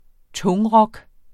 Udtale [ ˈtɔŋ- ]